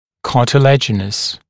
[ˌkɑːtɪ’læʤɪnəs][ˌка:ти’лэджинэс]хрящевой